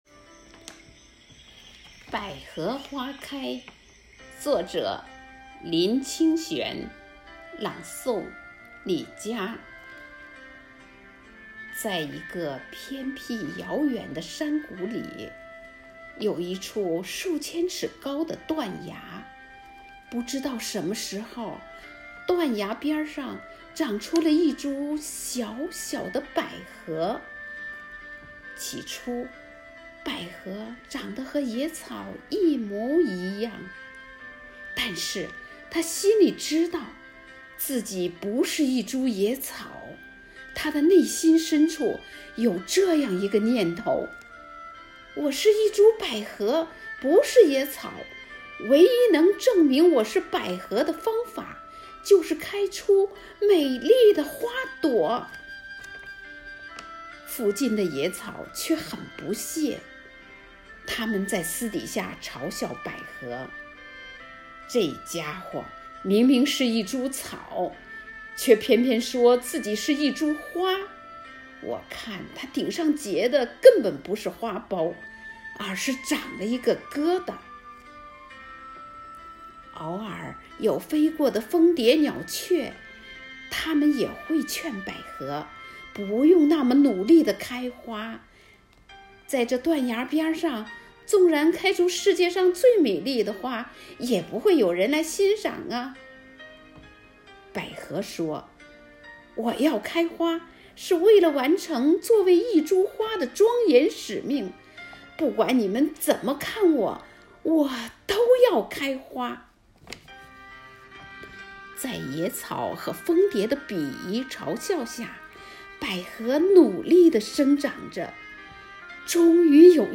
“生活好课堂幸福志愿者广外科普大学朗读服务（支）队”是“生活好课堂幸福志愿者朗读服务队”的第二支队伍，简称“广外科普大学朗读支队”。“生活好课堂”的九岁生日当晚，全体队员演绎了一场激情澎湃的朗诵会。
《百合花开》独诵